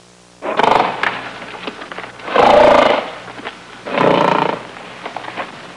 Big Mountain Lion Sound Effect
big-mountain-lion.mp3